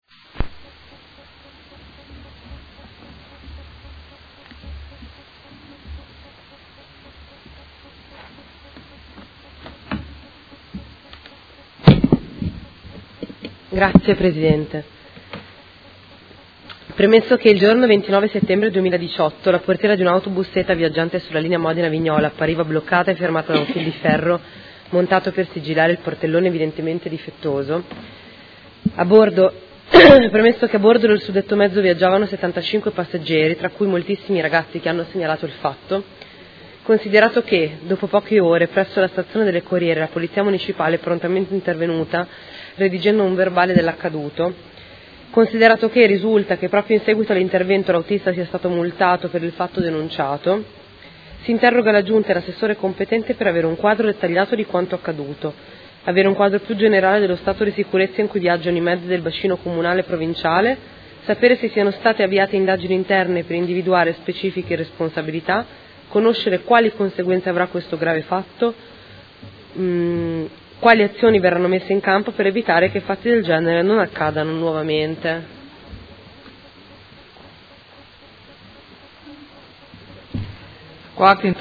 Seduta del 24/01/2019. Interrogazione della Consigliera Di Padova (PD) avente per oggetto: Sicurezza dei mezzi SETA, accadimento del 29 settembre 2018